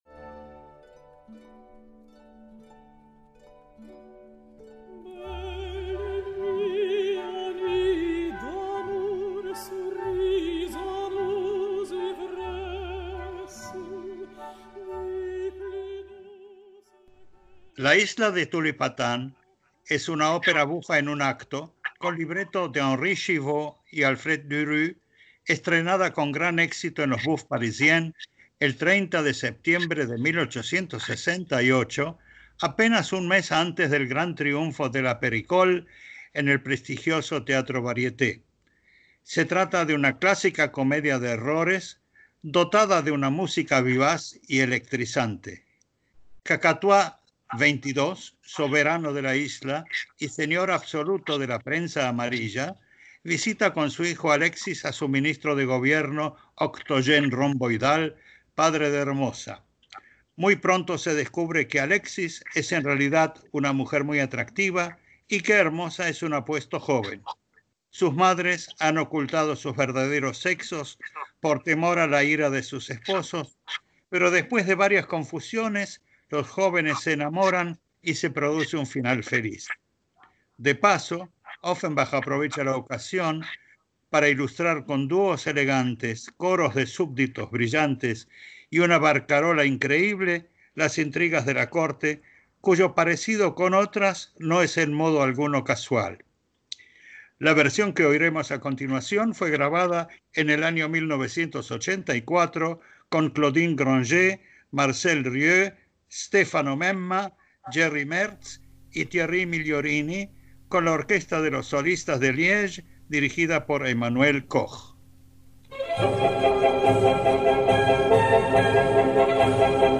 opera bufa en un acto
Se trata de una clásica comedia de errores, dotada de una música vivaz y electrizante.